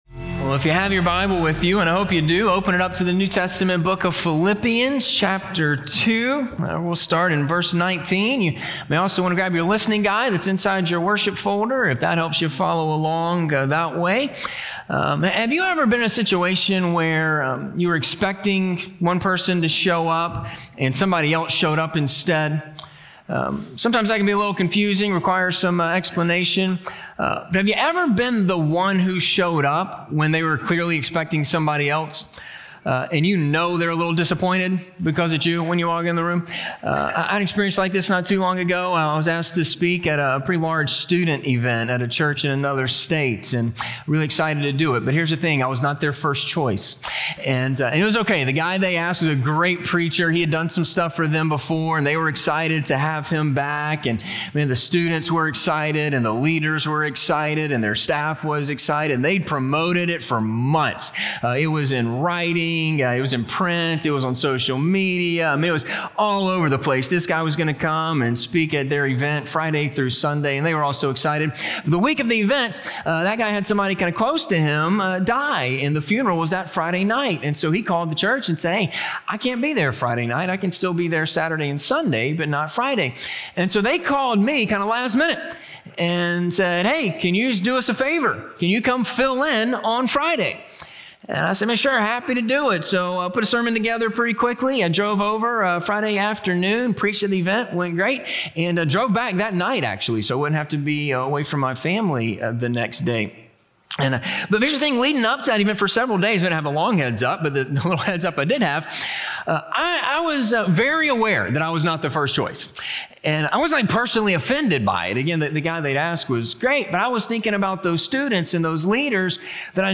Two Examples of Faithful Living - Sermon - Ingleside Baptist Church